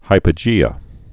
(hīpə-jēə)